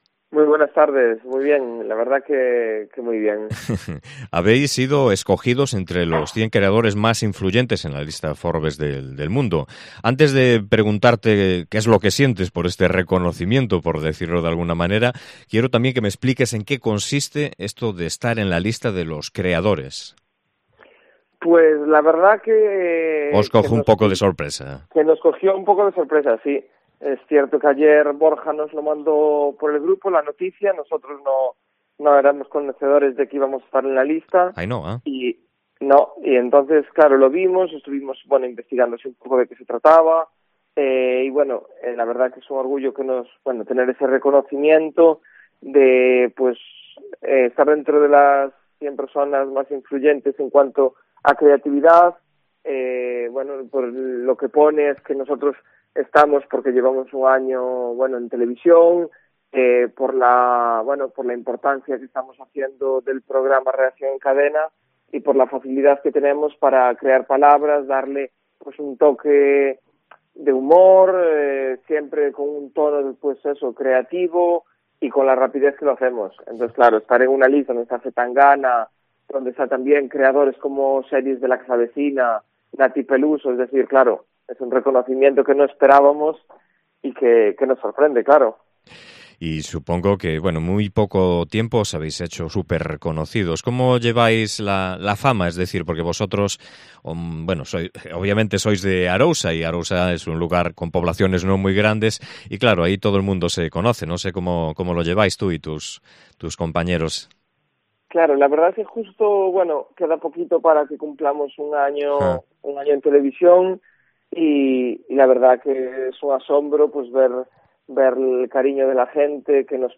Entrevista con Raúl Santamaría, "Mozo de Arousa" y diputado autonómico